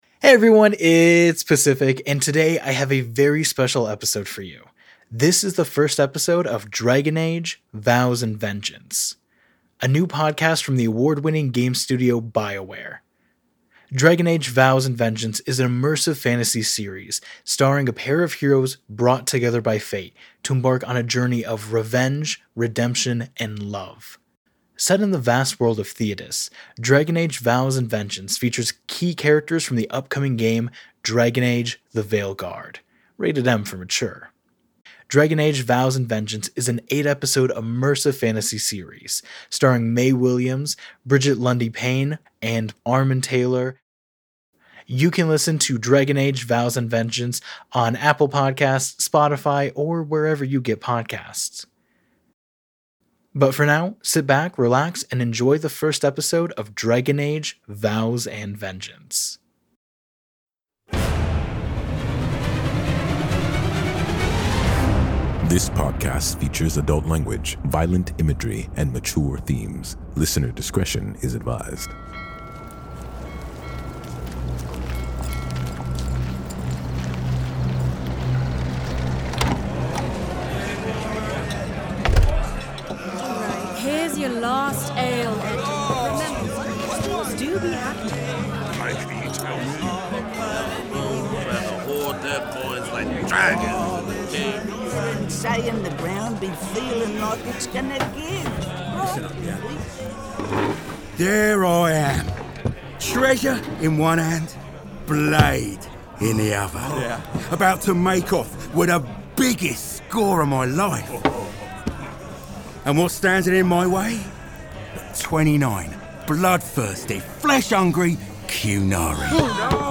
Arts, Society & Culture, Tv & Film, Drama, Fiction, Science Fiction